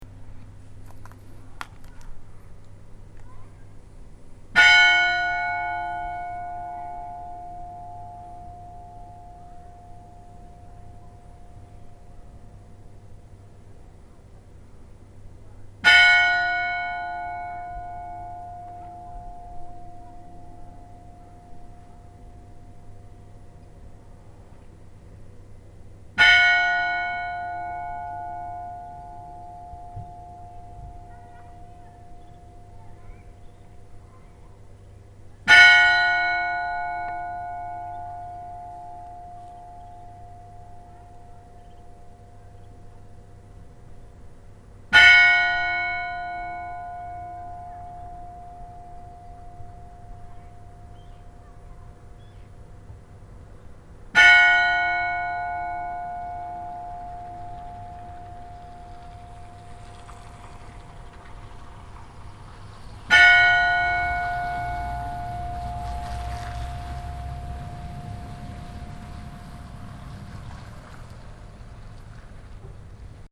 Í turni kirkjunnar eru tvær stórar klukkur sem keyptar voru árið 1928 og er þeim handhringt. Til þess að auðvelda hringingu eru lóð á móti hringiköðlunum og hefur það þau áhrif að klukkurnar hringja virðulega með nokkuð jöfnu bili.
Þegar kista kemur til eyjarinnar er líkhringing slegin á stærri klukkuna.
hriseyjarkirkja_likhringing.mp3